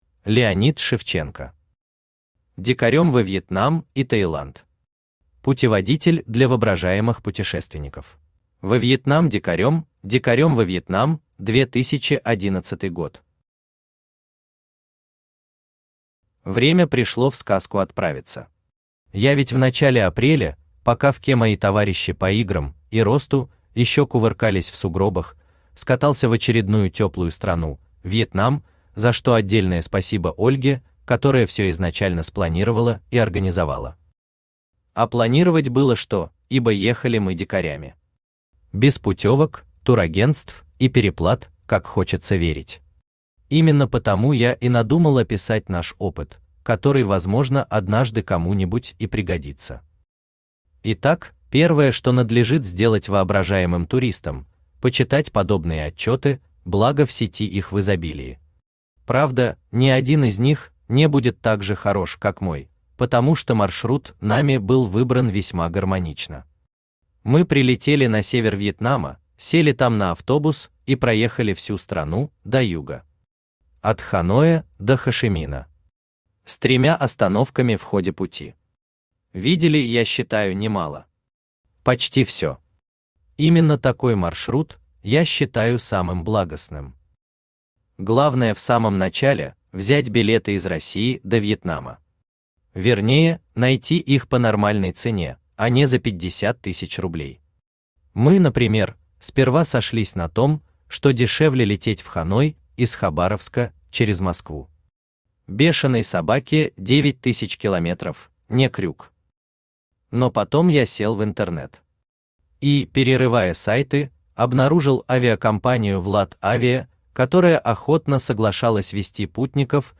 Аудиокнига Дикарём во Вьетнам и Таиланд. Путеводитель для воображаемых путешественников | Библиотека аудиокниг
Путеводитель для воображаемых путешественников Автор Леонид Шевченко Читает аудиокнигу Авточтец ЛитРес.